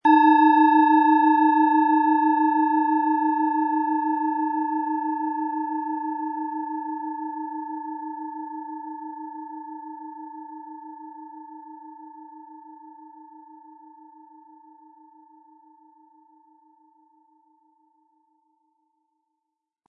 Planetenschale® Sinnlich Sein und Fühlen & Leidenschaftlich sein mit Eros, Ø 10,4 cm, 100-180 Gramm inkl. Klöppel
Den passenden Schlägel erhalten Sie kostenlos mitgeliefert, er lässt die Planetenton-Klangschale Eros harmonisch und wohltuend ertönen.
Die Frage wie diese angebotene Schale klingt, beantwortet unser Klangbeispiel.
SchalenformBihar
MaterialBronze